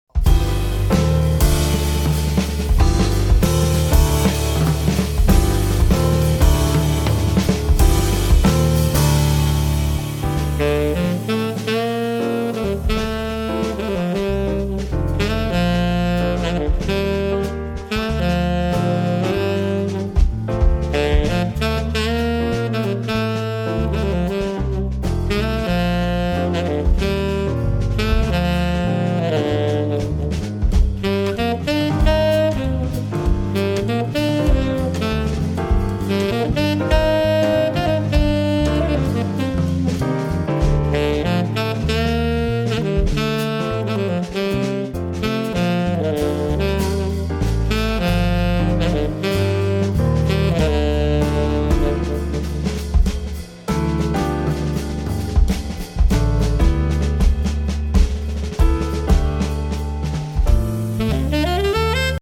sassofoni tenore e soprano
piano e tastiere
basso elettrico
batteria